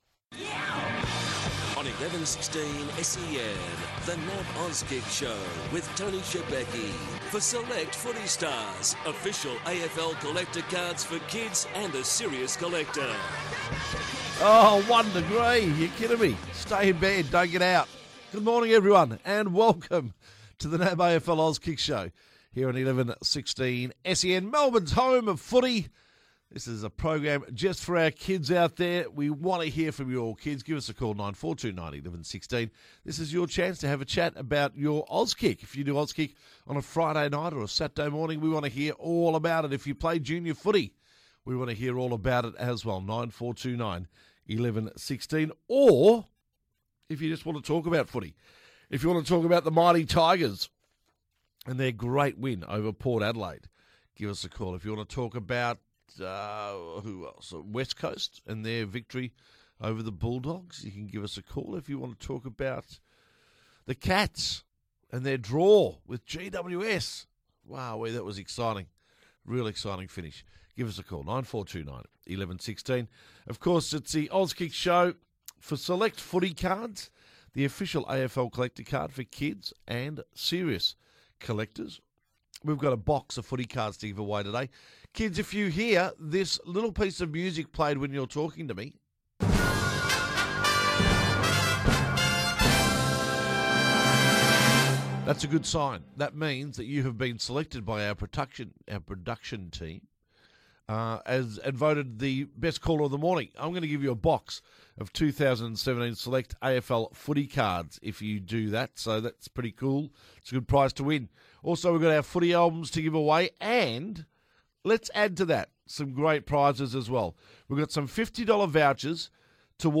takes all your calls on The Auskick Show!